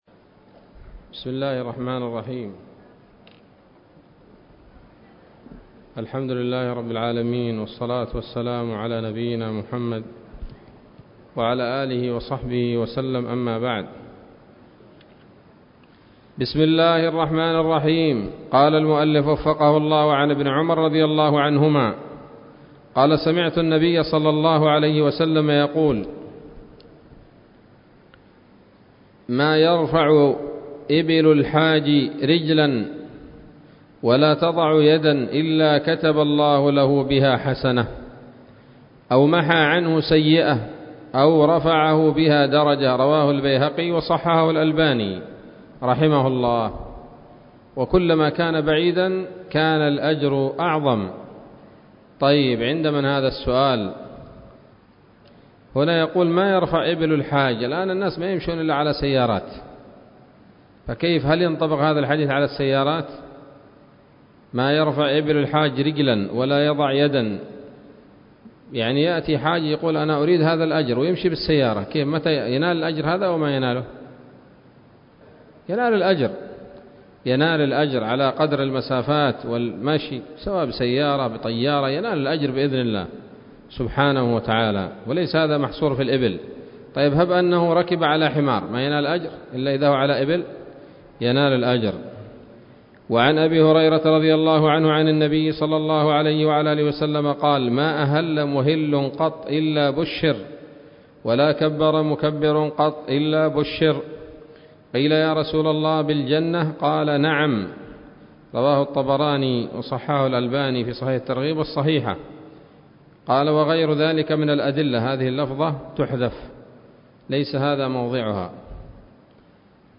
الدرس التاسع عشر من شرح القول الأنيق في حج بيت الله العتيق